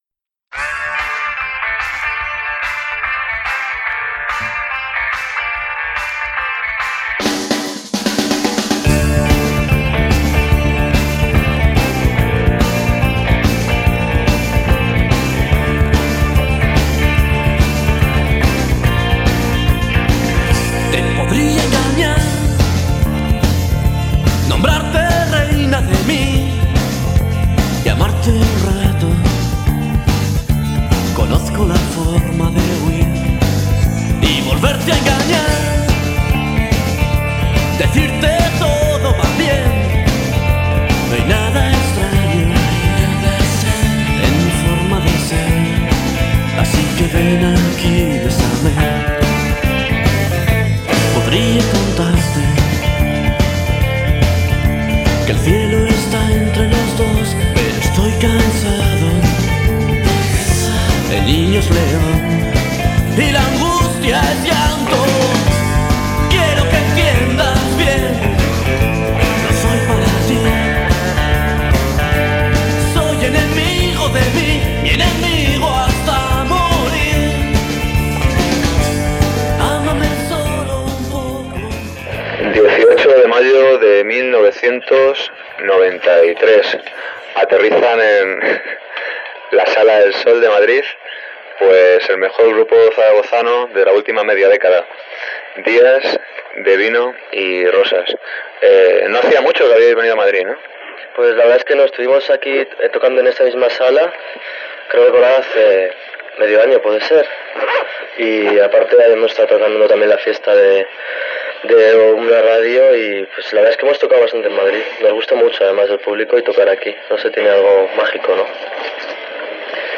La entrevista
La entrevista es, en sí misma, un documento único puesto que se trata del único audio de una entrevista radiofónica que hasta el momento hemos conseguido.
Lo he editado en lo posible para eliminar unas interferencias feotas, a cambio acuchilla un poco, pero se entiende perfectamente: